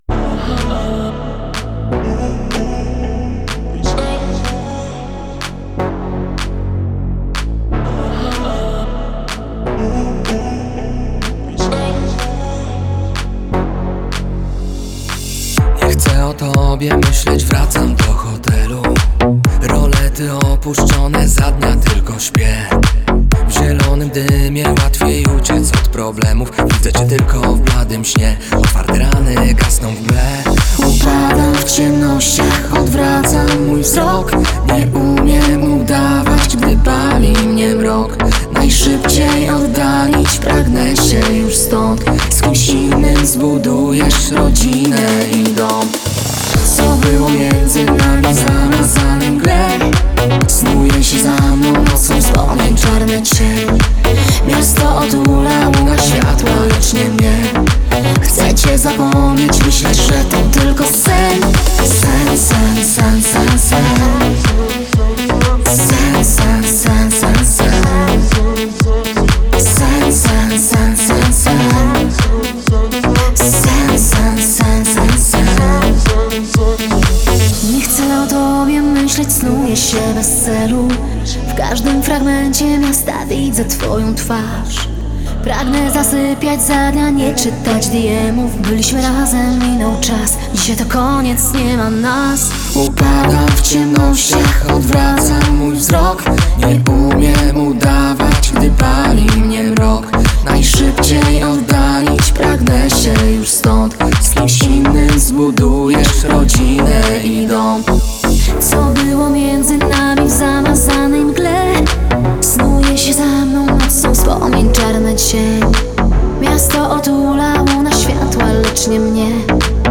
damsko-męski duet – elektroniczny sztos!